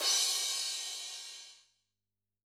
CYCdh_Kurz07-Crash01.wav